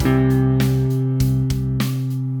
Take the time and listen to how each note from the scale sounds against the chord.
Notice how some notes sound more at home or more stable than others.